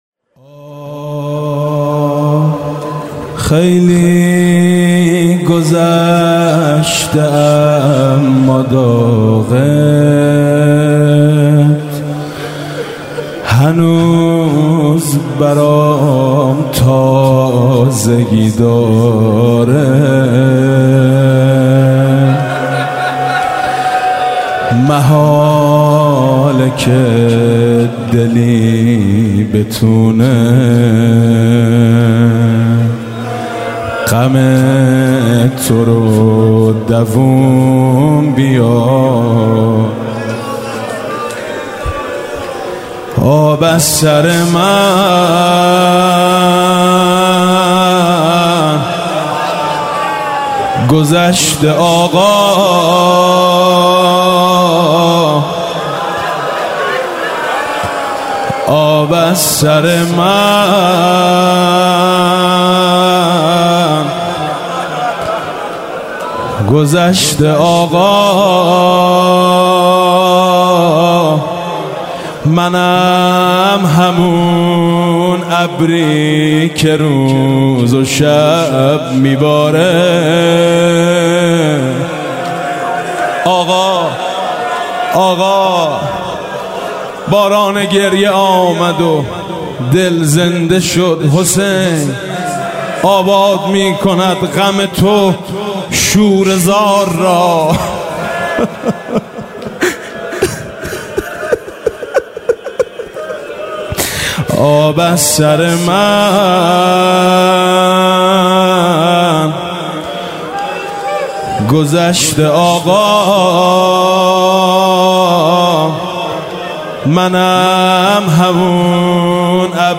حاج میثم مطیعی/شب دوم محرم الحرام 95/هیئت میثاق با شهدا
زمزمه/چی میشه من هم برات فداشم